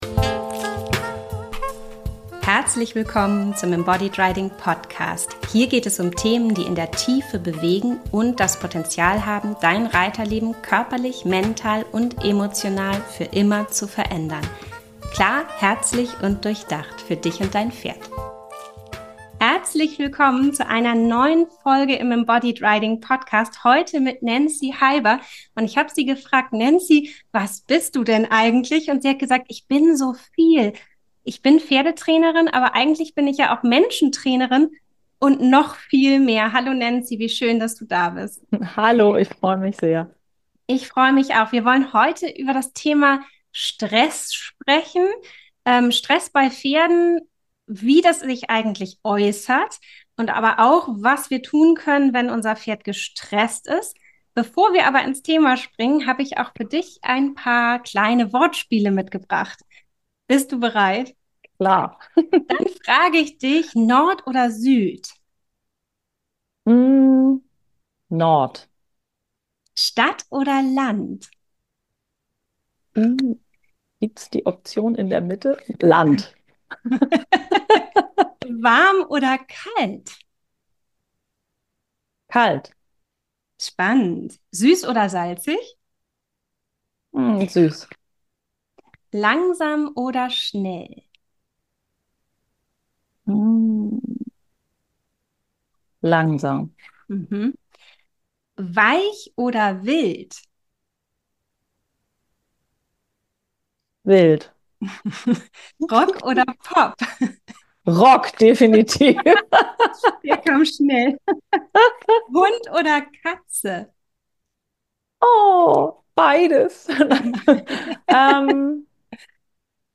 Ein Gespräch, das ganz sicher hilft, die Welt aus der Wahrnehmung deines Pferdes besser zu verstehen und eure Beziehung zu stärken.